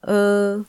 雅文檢測音
e_-15db.mp3